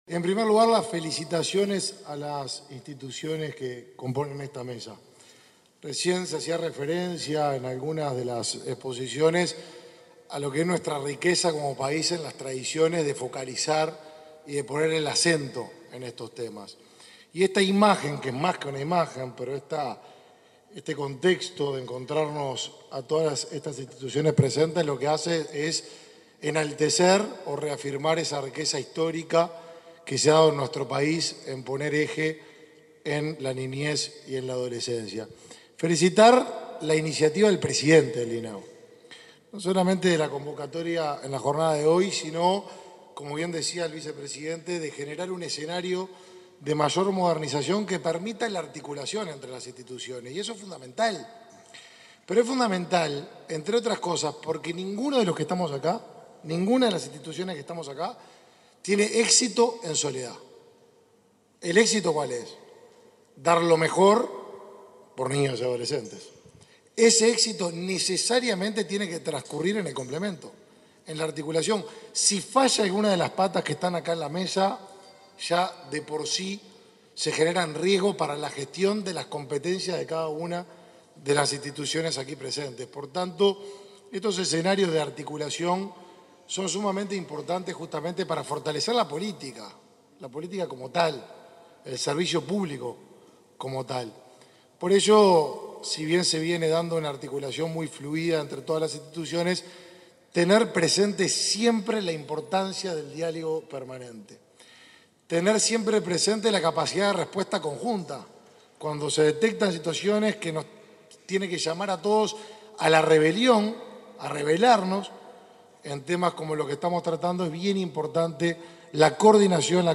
Instalación del Consejo Consultivo Honorario de los Derechos del Niño y el Adolescente 19/11/2021 Compartir Facebook X Copiar enlace WhatsApp LinkedIn Este viernes 19, quedó instalado en Montevideo el Consejo Consultivo Honorario de los Derechos del Niño y el Adolescente, ceremonia en la que participaron el ministro de Desarrollo Social, Martín Lema; el presidente del INAU, Pablo Abdala; el presidente de ASSE, Leonardo Cipriani, y el presidente del Codicen, Robert Silva.